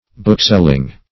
Bookselling \Book"sell`ing\, n. The employment of selling books.